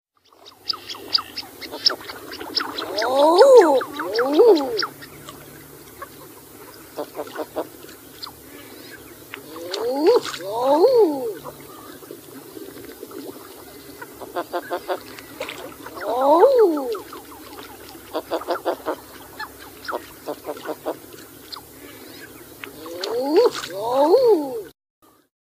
Calls, Whistles & Whoops
13_CallsWhistlesWhoops.mp3